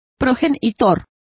Complete with pronunciation of the translation of progenitor.